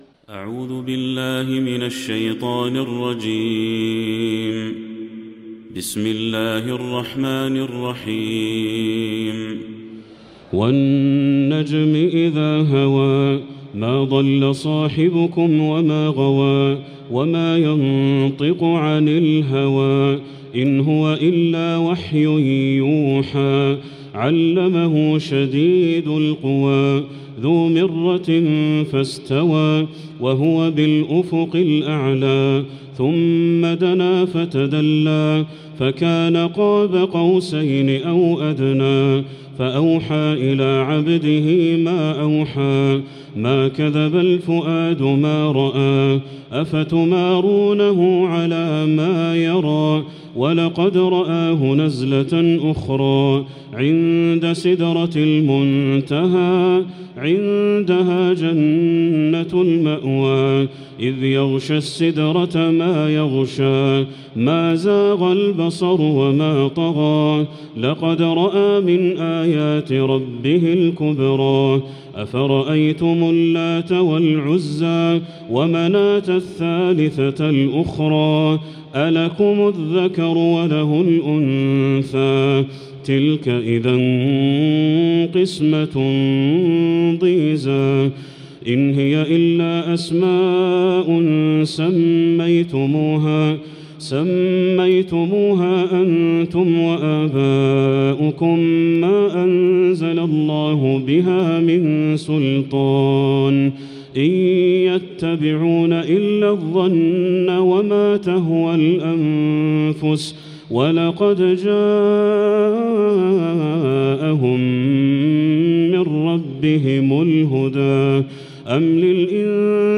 سورة النجم كاملة > السور المكتملة للشيخ بدر التركي من الحرم المكي 🕋 > السور المكتملة 🕋 > المزيد - تلاوات الحرمين